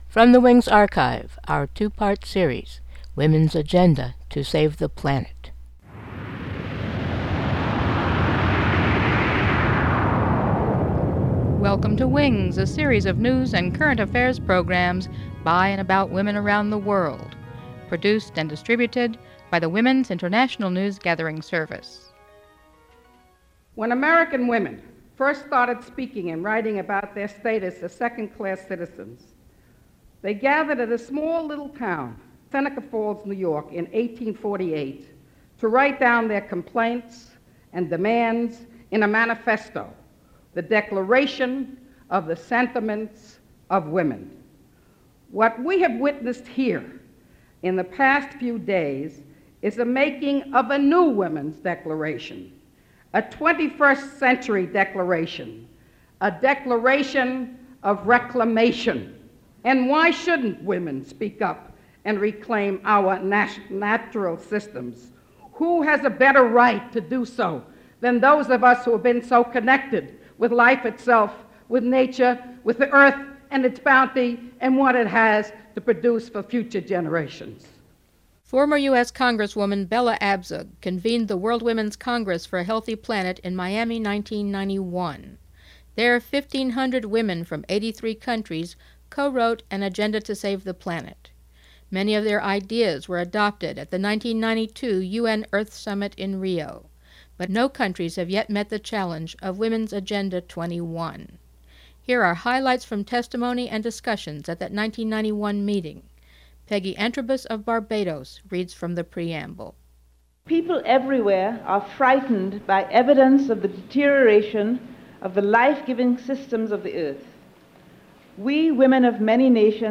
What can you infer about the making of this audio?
WEDO held this event in 1991 to create consensus among women for the 1992 World Summit on the Environmentand beyond.